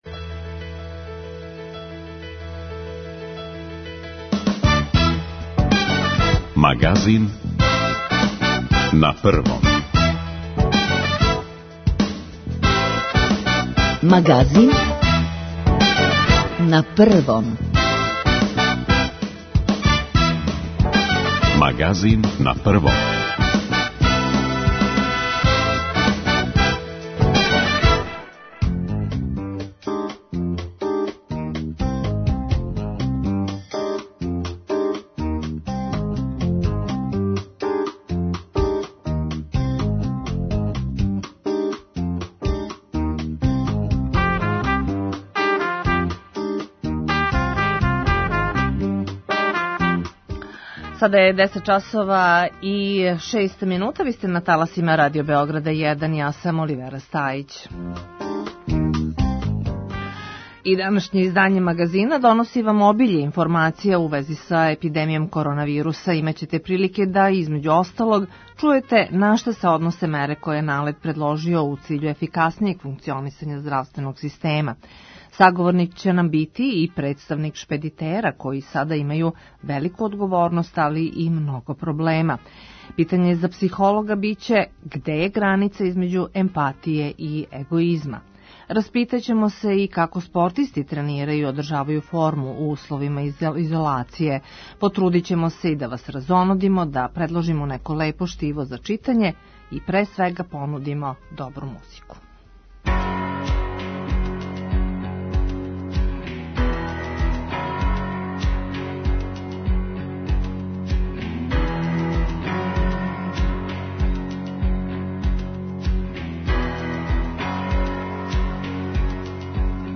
Саговорник ће нам бити и представник шпедитера који сада имају велику одговорност, али и много проблема.